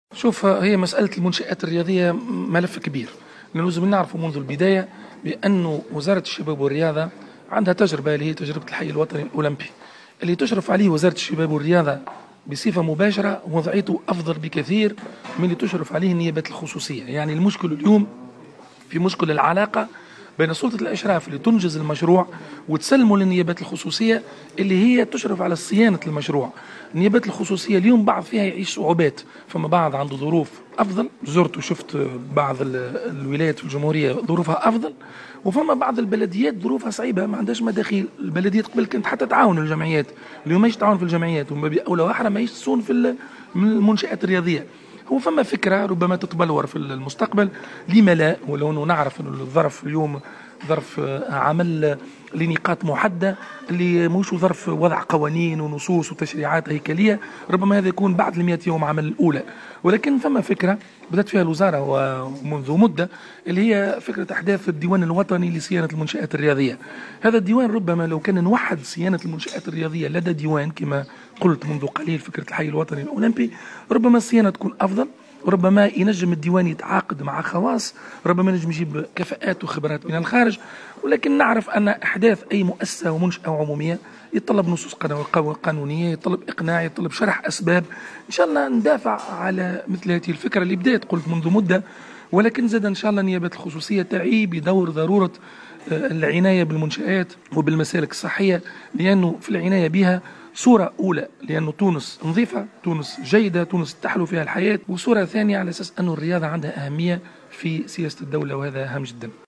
و أعلن وزير الشباب و الرياضة في تصريح خاص لجوهرة أف أم عن عزم الوزارة بعث ديوان وطني لصيانة المنشآت الرياضية تعهد له صيانة الملاعب و القاعات و الفضاءات الرياضية من خلال الإشراف المباشر على صيانتها أو التعاقد مع شركات خاصة تكون مهامها الحرص على الصيانة المتواصلة و تعوض دور النيابات الخصوصية التي يمر أغلبها بظروف صعبة تجعلها غير قادرة على توفير الإمكانيات المالية اللازمة للغرض .